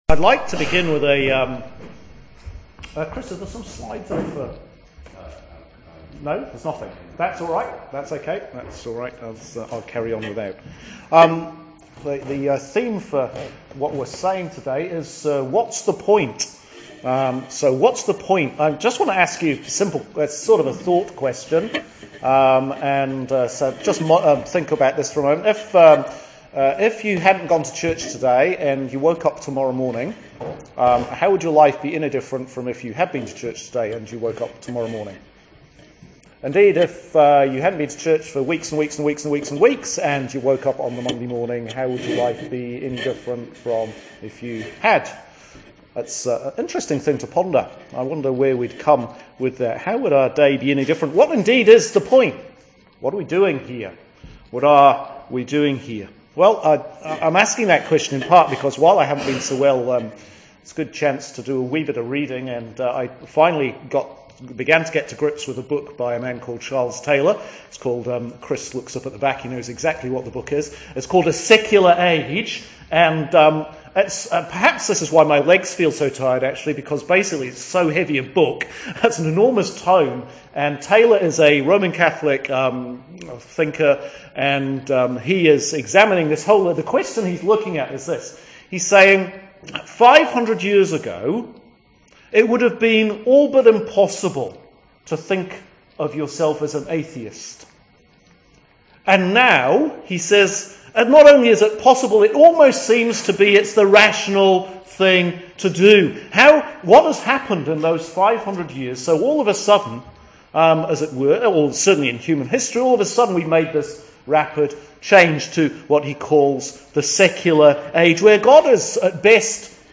Sermon for Sunday 13th – What’s the point . . .? Jesus and the ten lepers